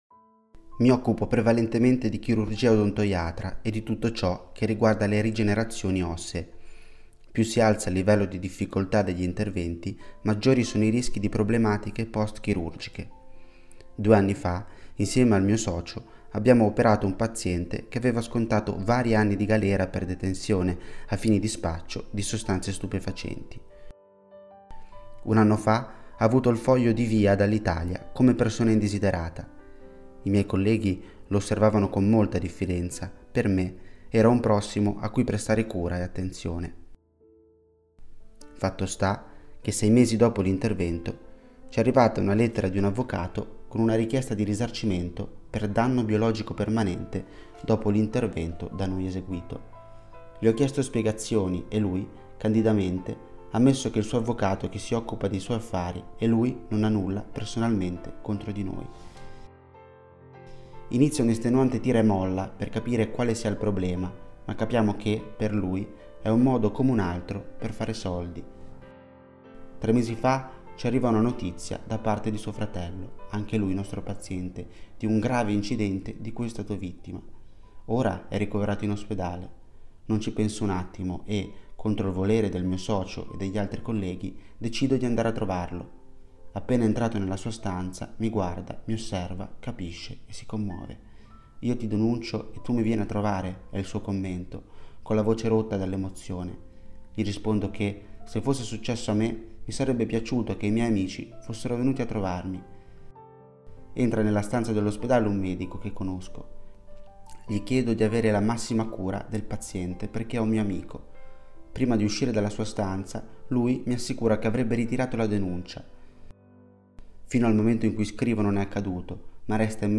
Storie > Audioletture